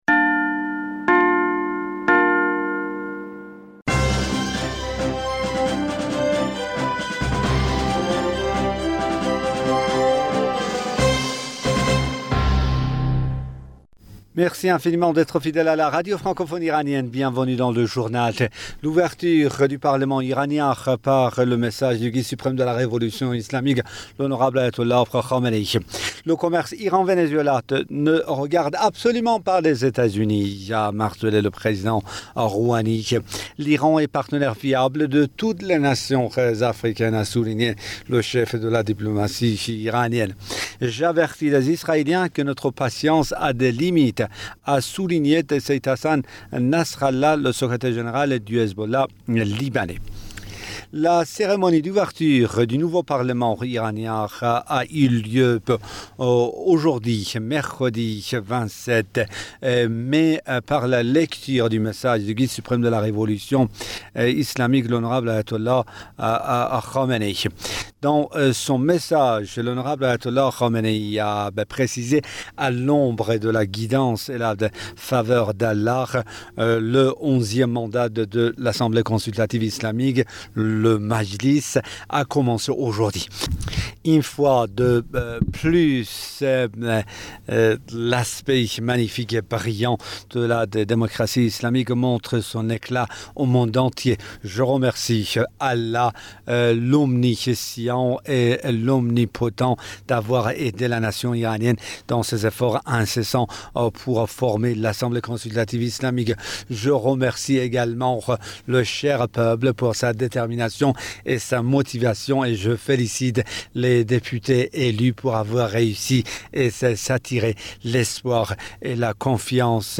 Bulletin d'information du 27 mai 2020